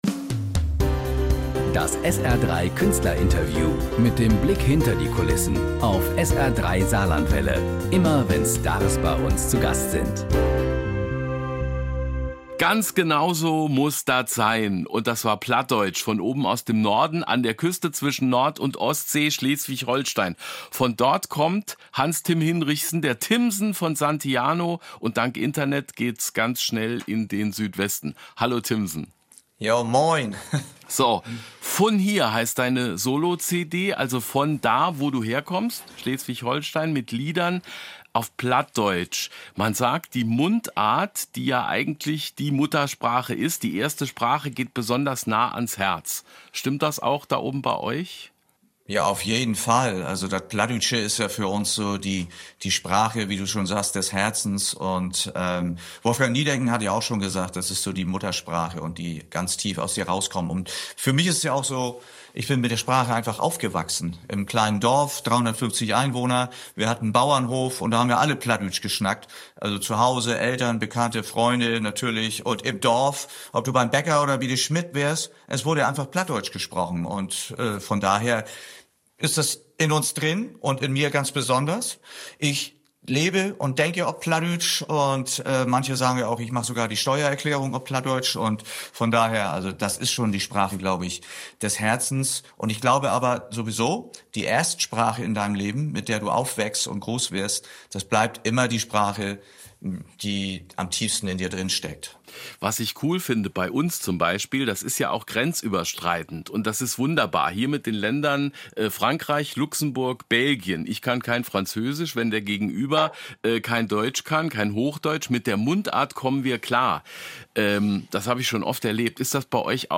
Das SR 3 Künstlerinterview. Mit dem Blick hinter die Kulissen.